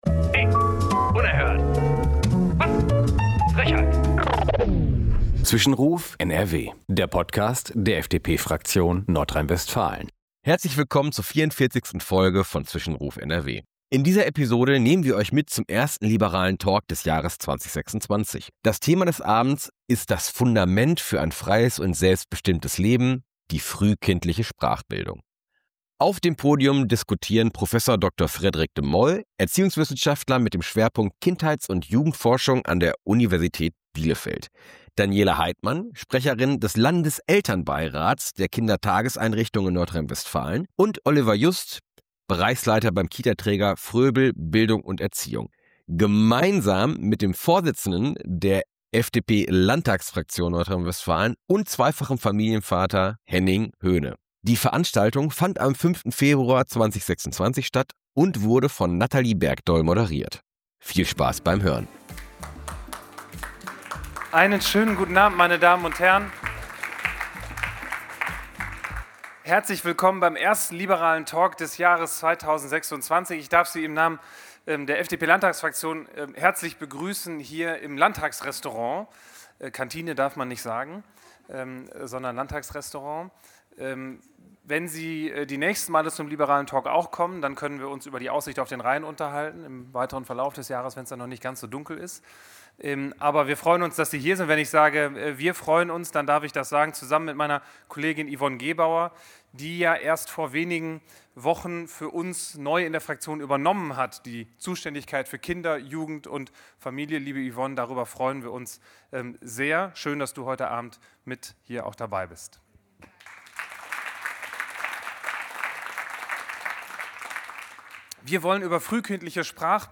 Beim ersten „Liberalen Talk“ 2026 der FDP-Landtagsfraktion diskutierte Henning Höne mit Experten aus Wissenschaft, Praxis und Elternschaft über die frühkindliche Sprachbildung als entscheidendes Fundament für Bildungserfolg und Chancengerechtigkeit.